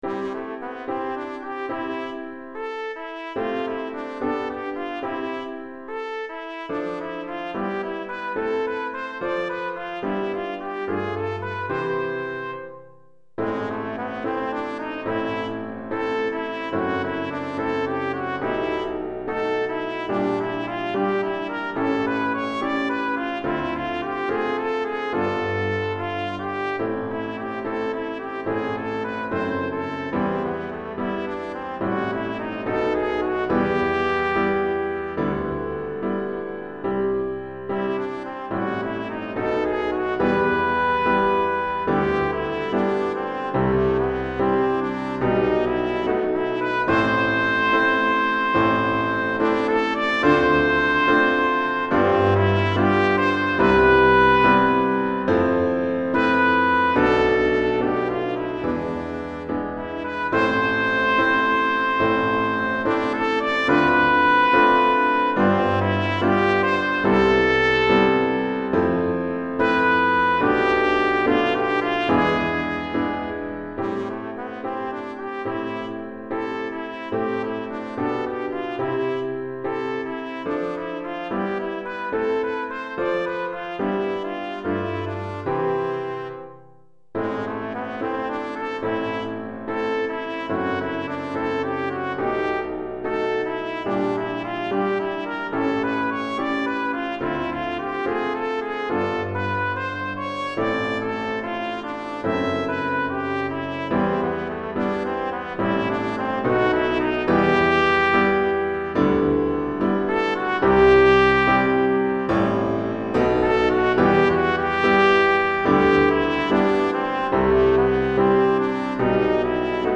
Trompette et Piano